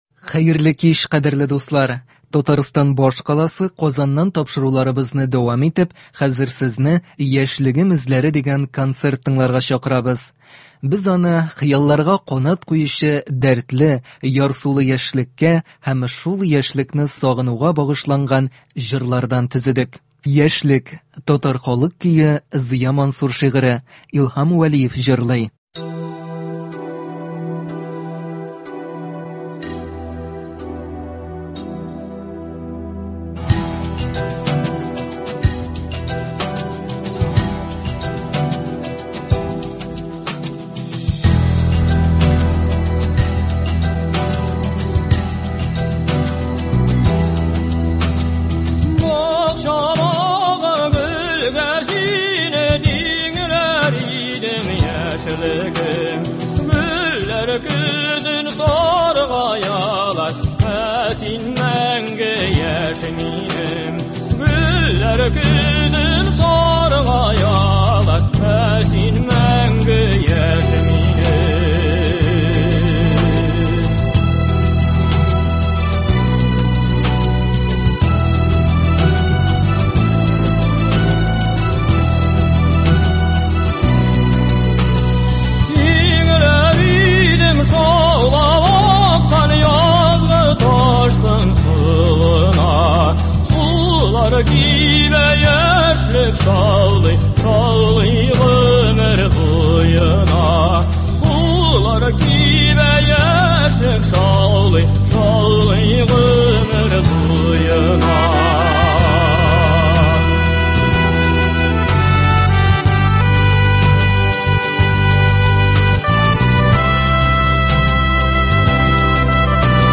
Көндезге концерт. Бүген — Россиядә Халыклар бердәмлеге көне.